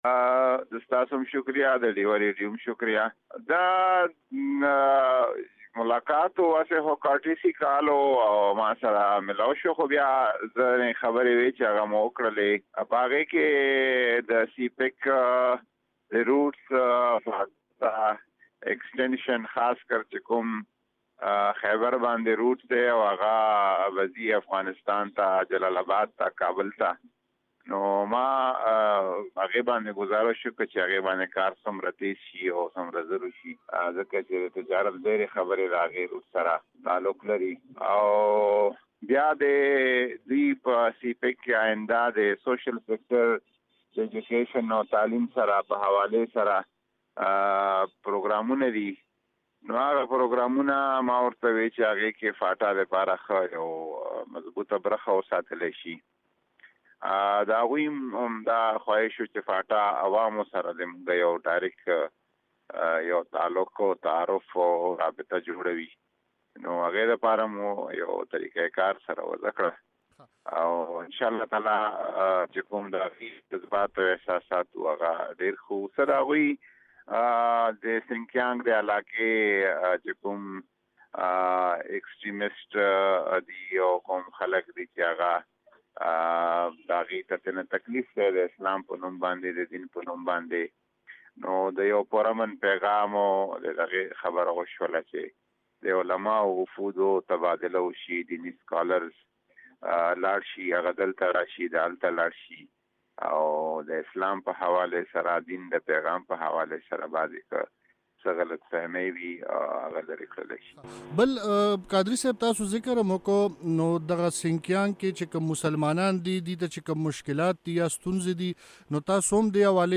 د پاکستان د مذهبي چارو وزير نورالحق قادري مرکه